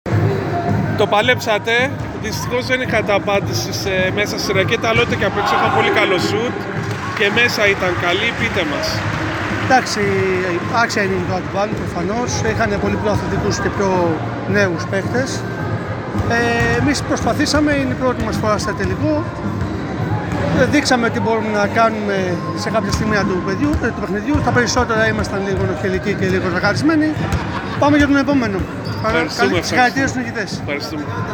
GAME INTERVIEWS:
Παίκτης ΟΛΥΜΠΙΑΚΗΣ ΖΥΘΟΠΟΙΙΑΣ